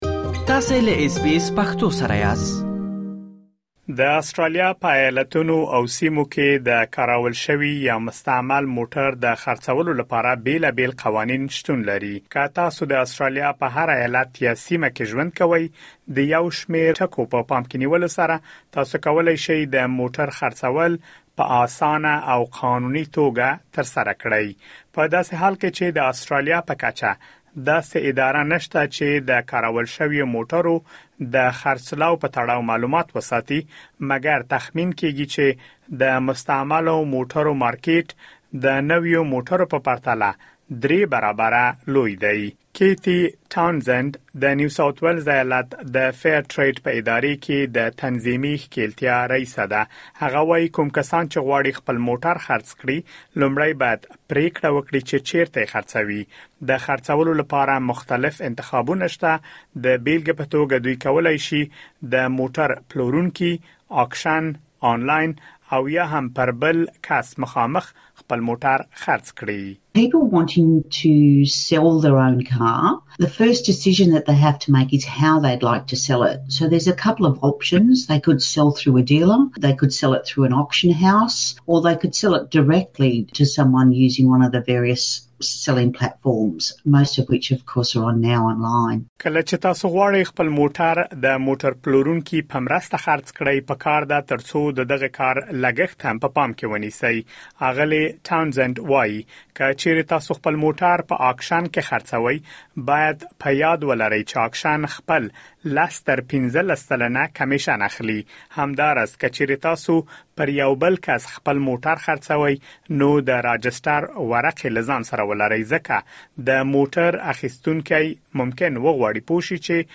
د آسټرالیا ایالتونه او سیمې د موټر پلورلو بېلابېل قوانین لري. په دغه رپوټ کې مو د یو شمېر داسې قوانینو په اړه معلومات را غونډ کړي چې د آسټرالیا په ایالتونو او سیمو کې یو ډول دي.